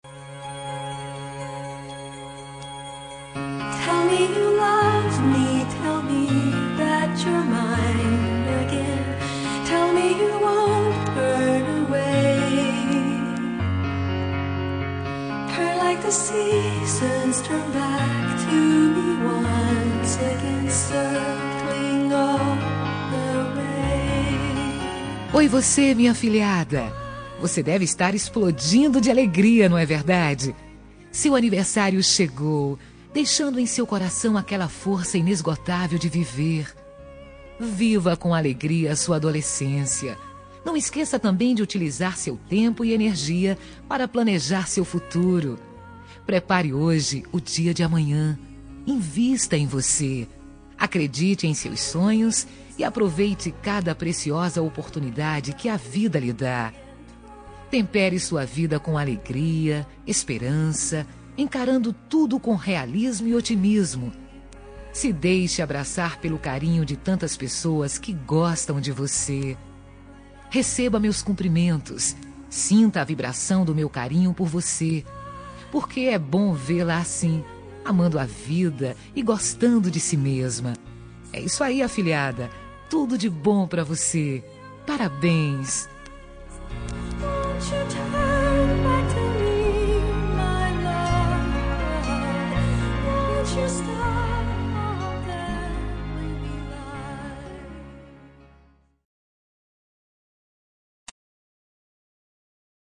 Aniversário de Afilhada – Voz Feminina – Cód: 2390